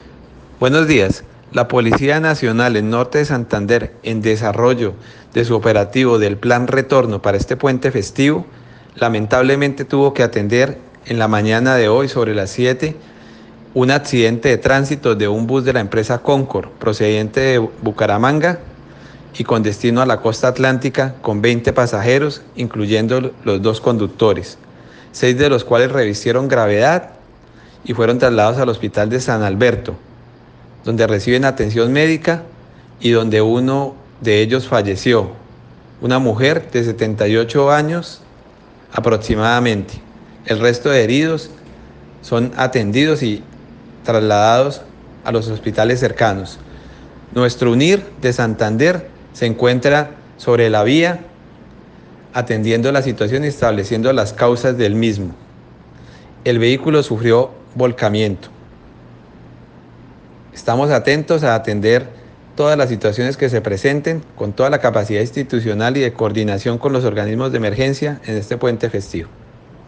Declaraciones del señor Coronel Carlos Martínez Comandante Departamento de Policía Norte de Santander.